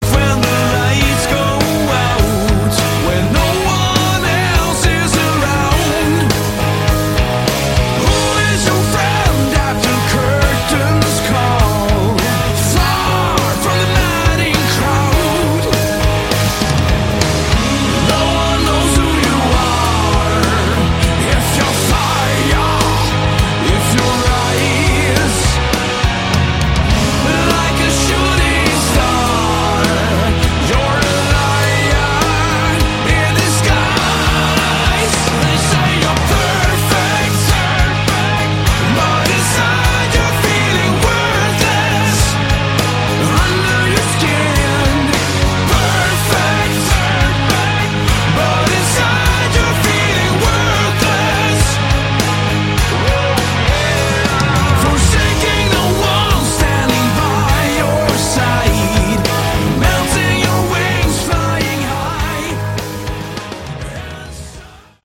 Category: Hard Rock
guitar, backing vocals
vocals
keyboards
bass
drums